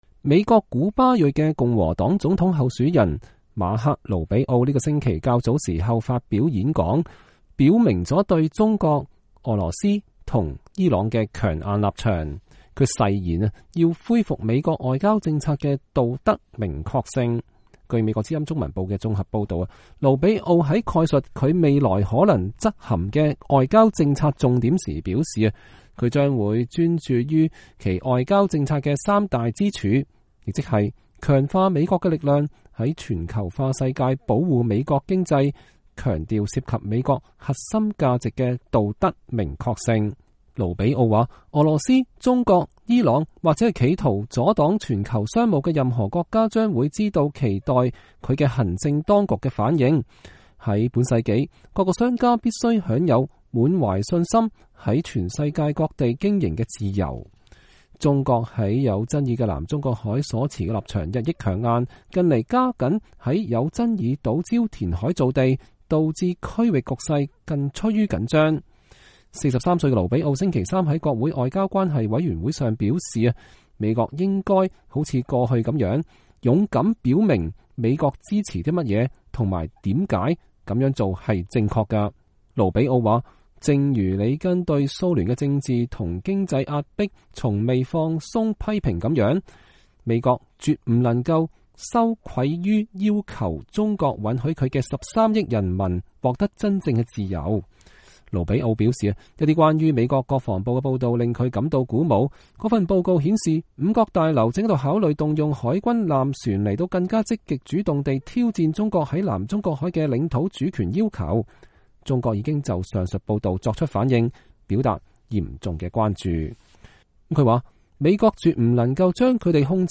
盧比奧參議員對美國的外交關係委員會的成員發表講話(2015年5月13日)